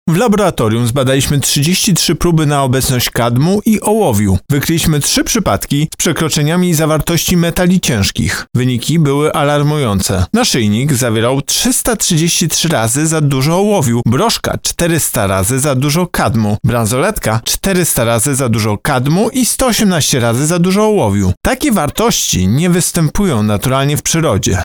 – tłumaczy prezes UOKiK, Tomasz Chróstny.